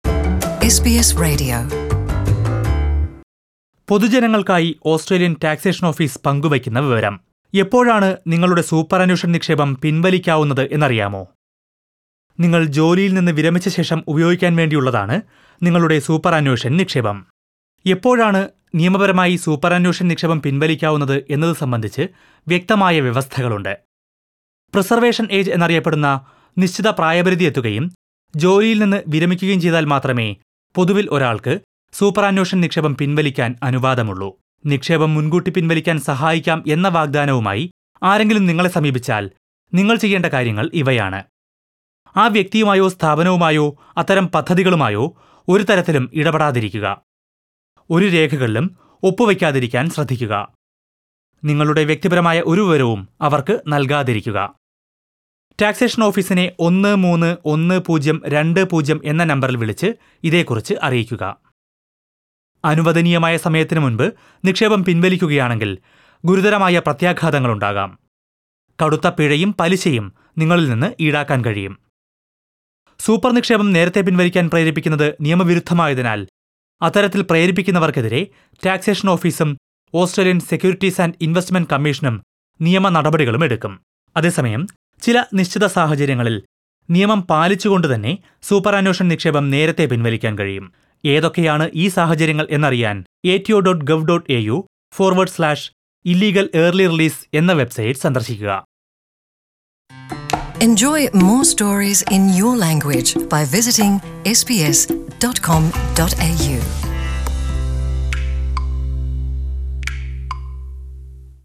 പൊതുജനങ്ങള്‍ക്കായി ഓസ്‌ട്രേലിയന്‍ ടാക്‌സേഷന്‍ ഓഫീസ് നല്‍കുന്ന അറിയിപ്പ്.